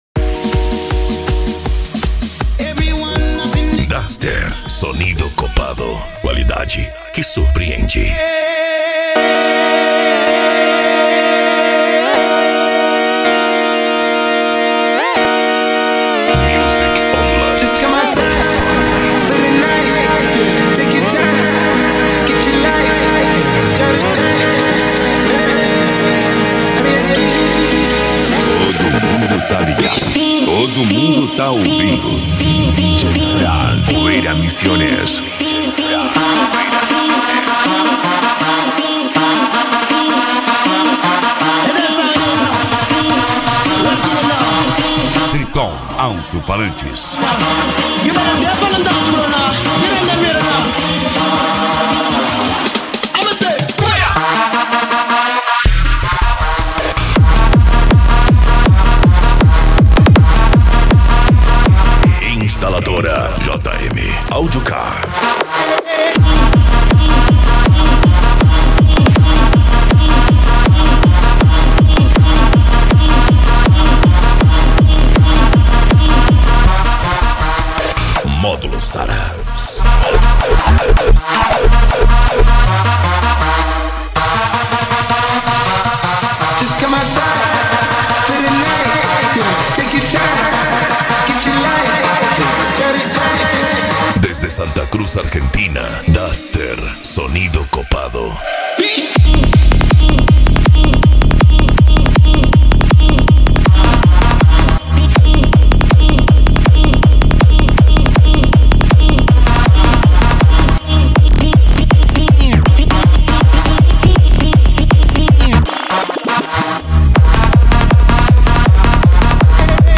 Eletronica
Psy Trance
Remix